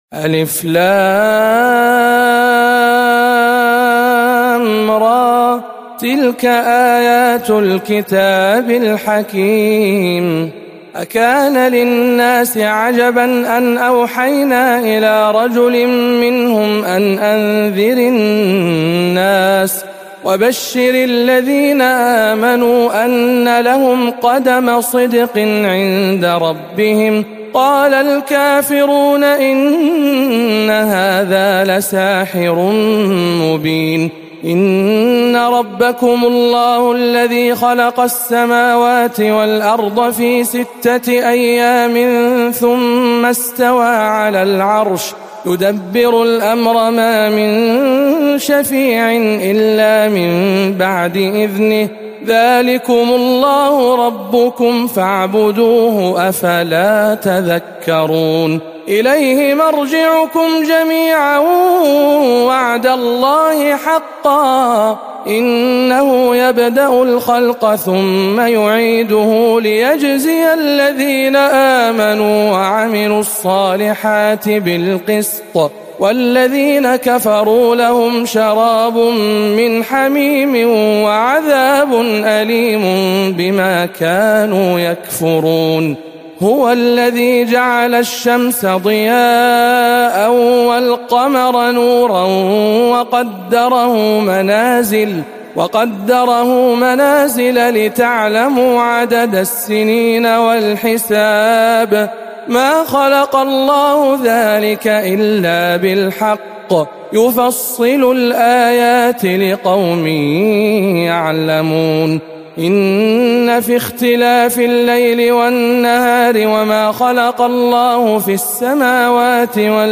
سورة يونس بجامع أم الخير بجدة - رمضان 1439 هـ